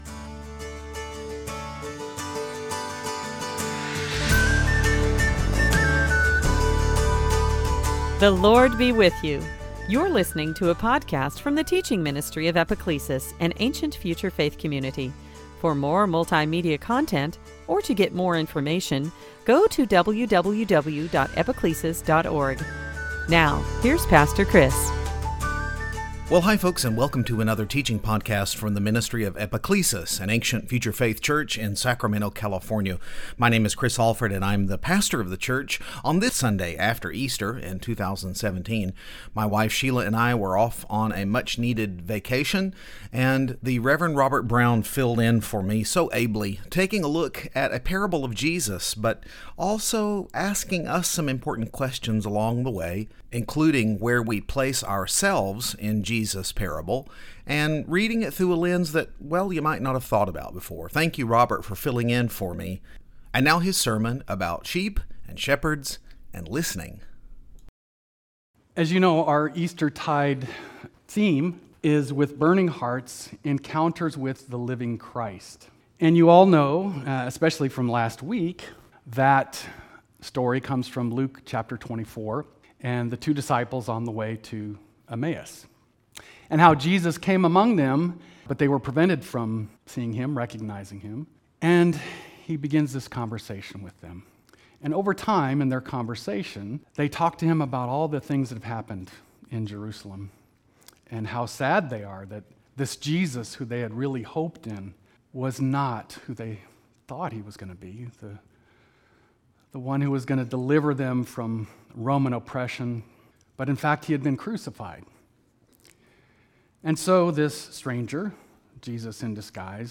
Series: Sunday Teaching On this fourth Sunday of Easter